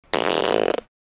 PIANETA GRATIS - Audio Suonerie - Persone - Rutti e Scoregge 05
fart-1.mp3